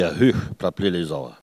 Elle crie pour appeler les oies
Saint-Jean-de-Monts